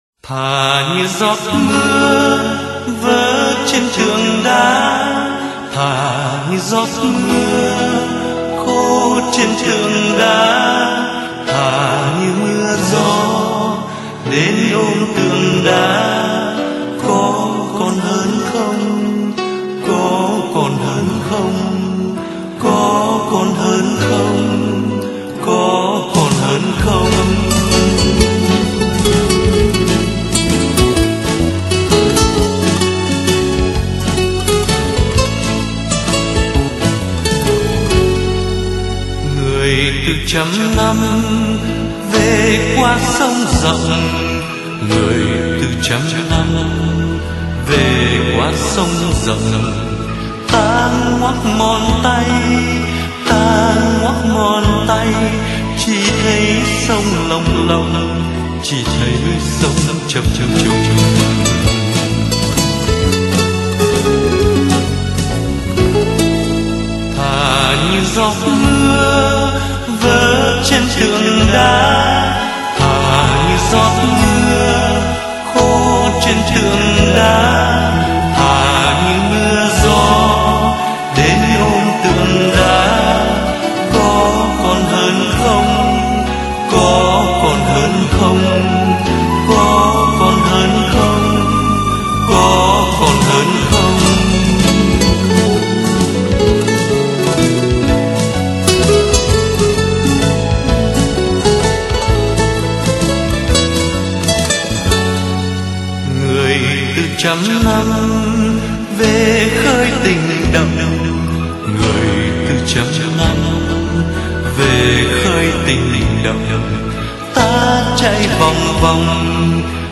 tình khúc